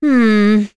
Selene-Vox-Deny1.wav